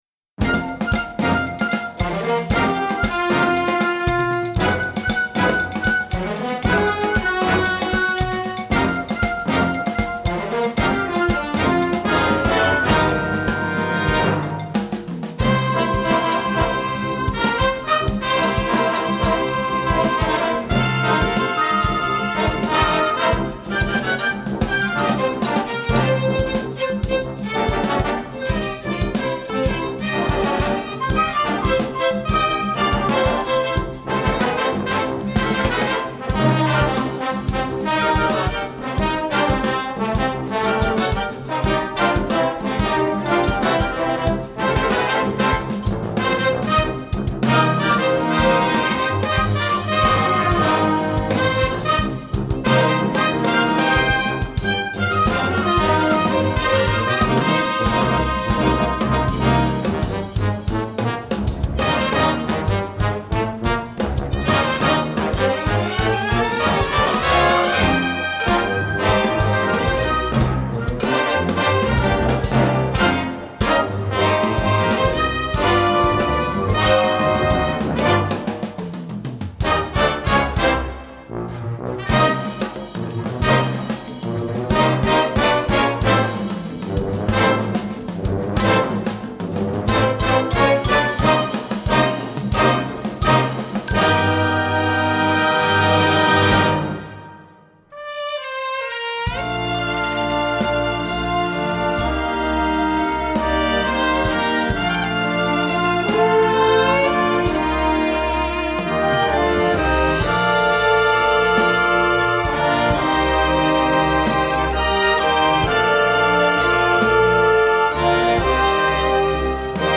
Instrumentation: full orchestra